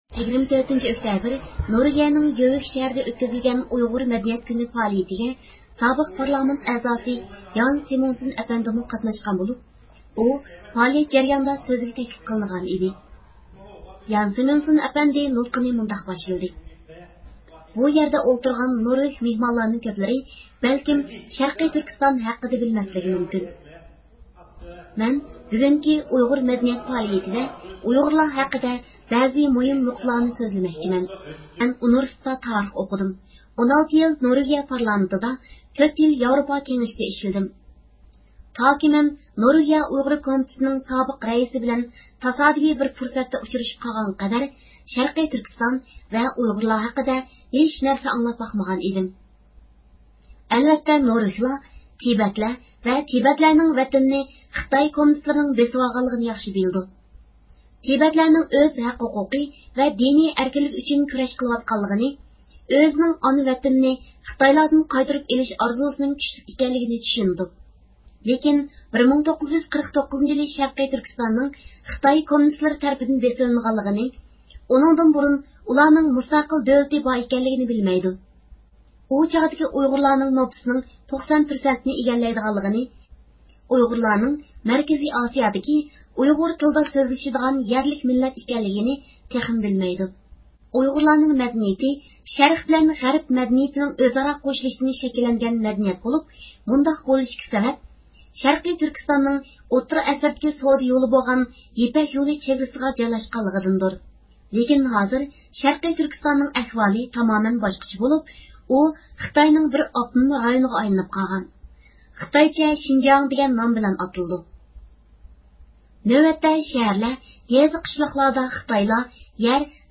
24- ئۆكتەبىر نورۋېگىيىنىڭ گجۆۋىك شەھىرىدە ئۆتكۈزۈلگەن ئۇيغۇر مەدەنىيەت كۈنى پائالىيىتىگە نورۋېگىيە سابىق پارلامېنت ئەزاسى جان سمونسەن ئەپەندىمۇ قاتناشقان.